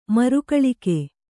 ♪ marukaḷike